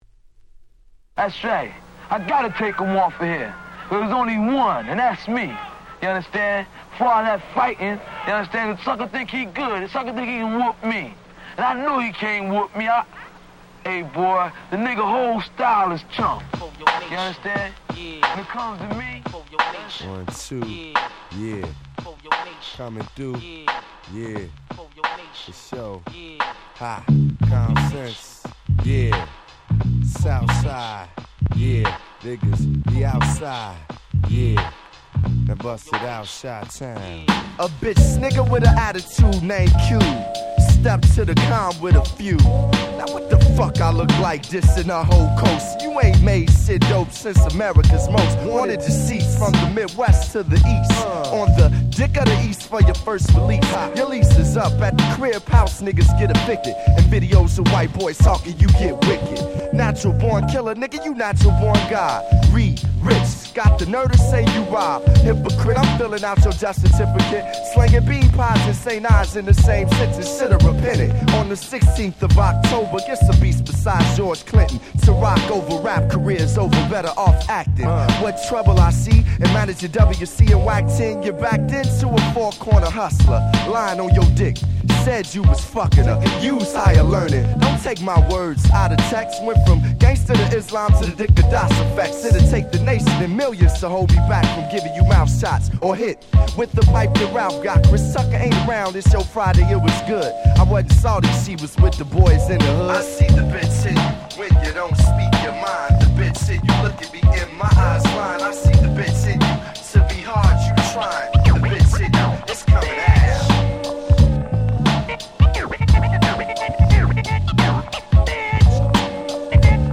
96' Smash Hit Hip Hop !!